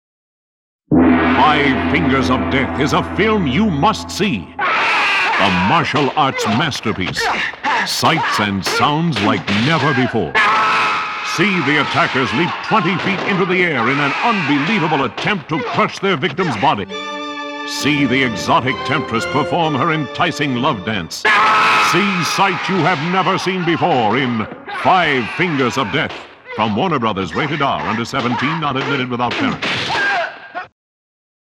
Radio Spots
So now, though, put on your gi or your Kung Fu uniform, assume your beginning stance, and listen to radio spots for the movie that started it all.